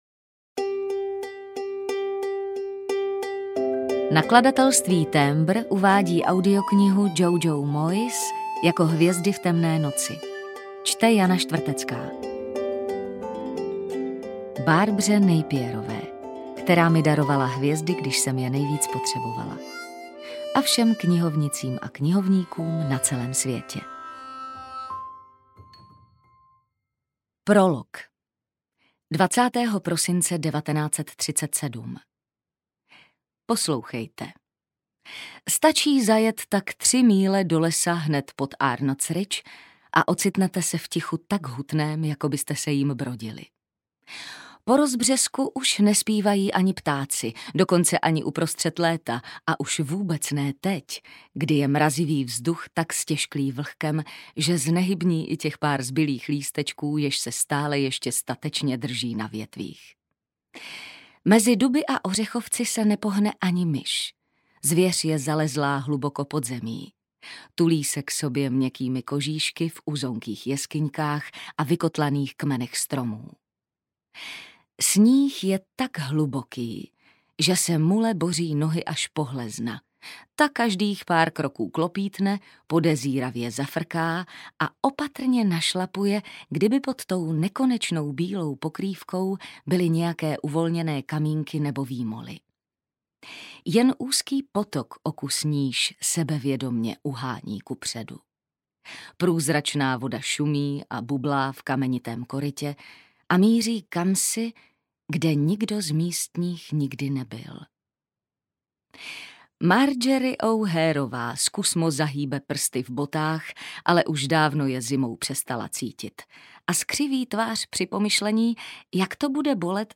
Jako hvězdy v temné noci audiokniha
Ukázka z knihy